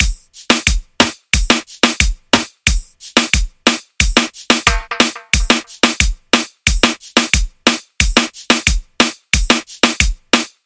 雷盖顿运动Clasico
描述：经典的雷盖顿鼓声循环
标签： 90 bpm Reggaeton Loops Drum Loops 1.79 MB wav Key : Unknown
声道立体声